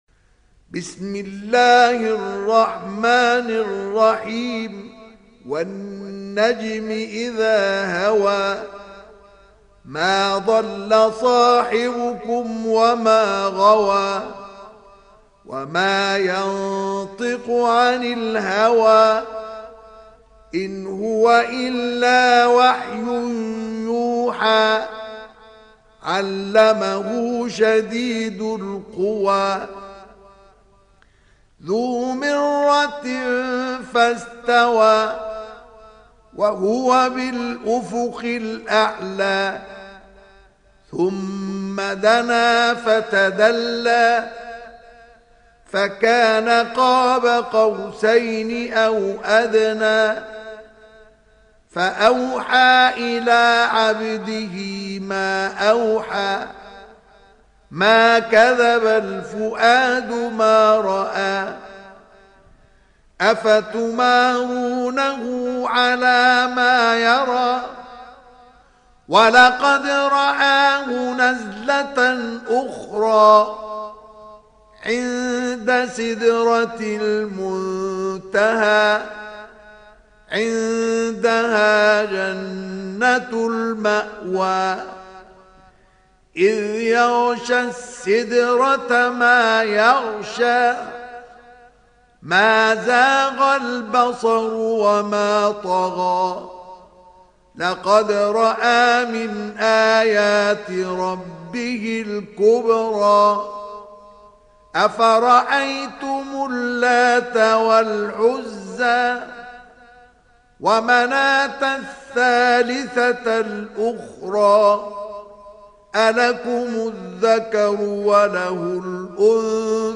Sourate An Najm Télécharger mp3 Mustafa Ismail Riwayat Hafs an Assim, Téléchargez le Coran et écoutez les liens directs complets mp3